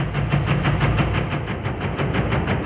Index of /musicradar/rhythmic-inspiration-samples/90bpm
RI_DelayStack_90-06.wav